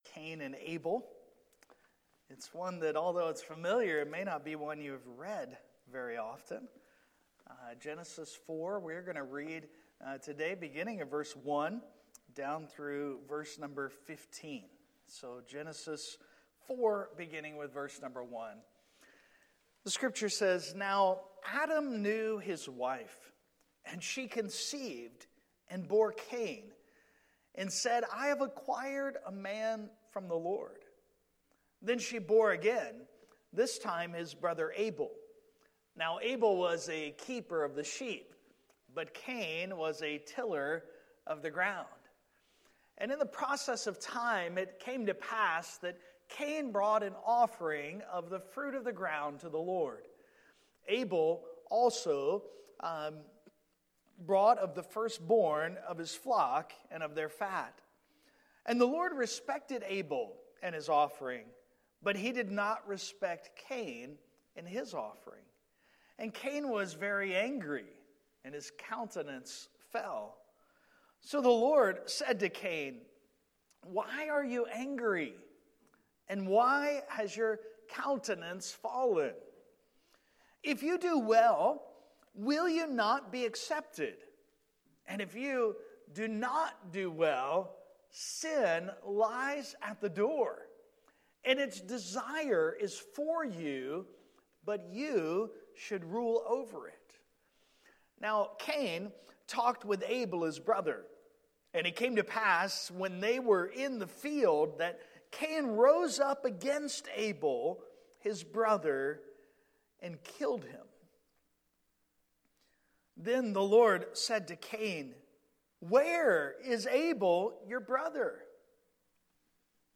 Passage: Genesis 4:1-15 Service Type: Sunday Morning